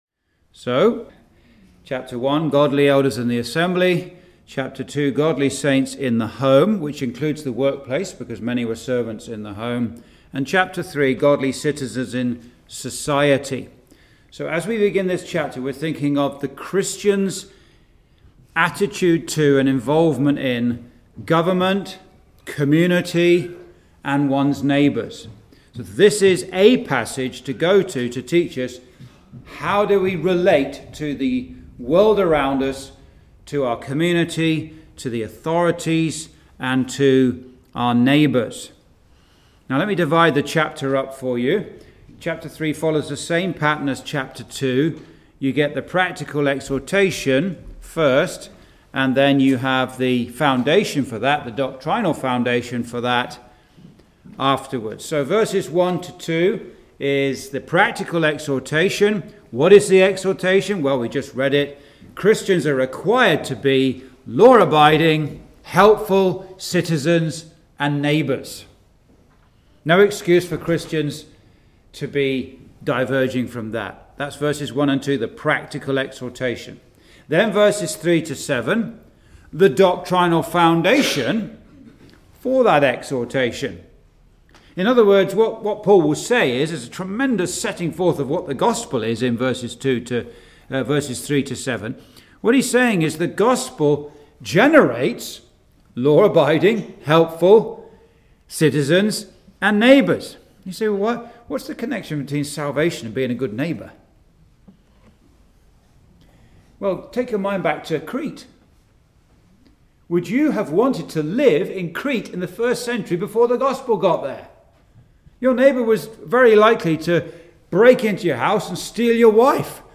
(Message preached in Chalfont St Peter Gospel Hall, 2022)
Verse by Verse Exposition